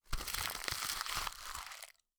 crumples2.wav